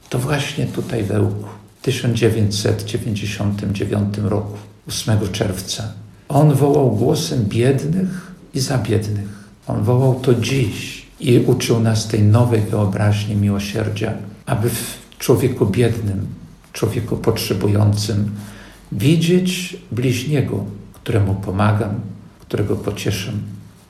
– Jan Paweł II przez całe życie uczył nas trwania w Bogu, uczył nas modlitwy – mówi biskup ełcki.